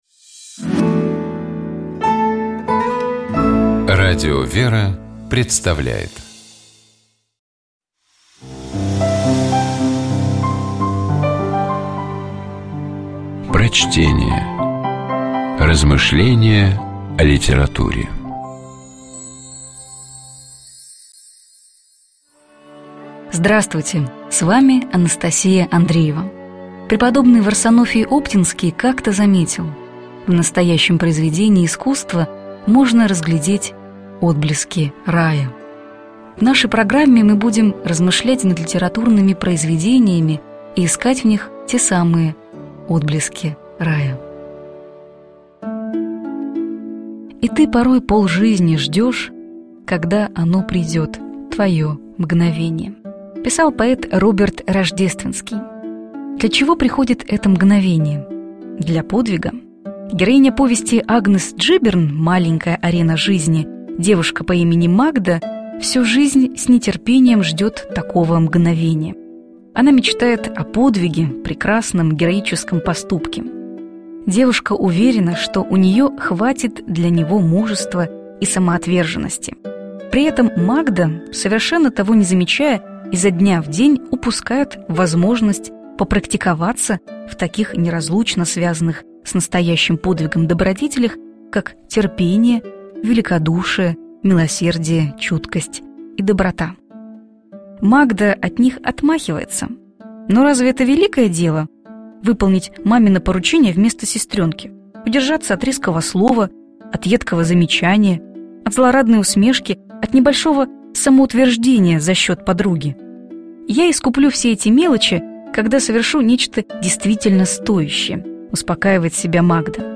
Prochtenie-A_-Dzhibern-Malenkaja-arena-zhizni-Smysl-malyh-usilij.mp3